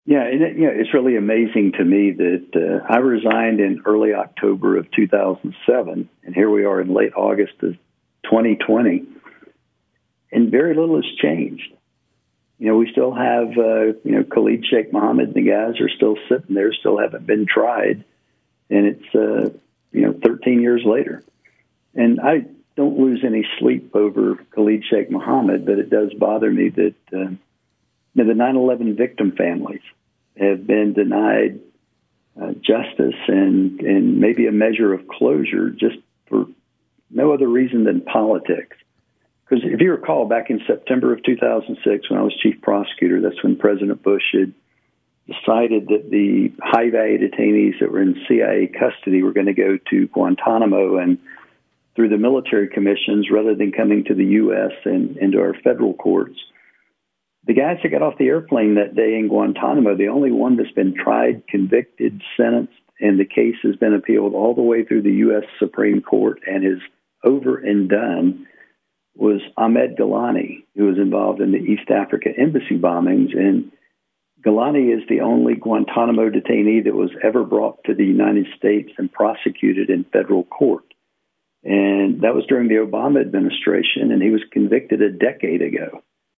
In-Depth Interview: Former Gitmo Prosecutor Col. Morris Davis Is Running for Congress in North Carolina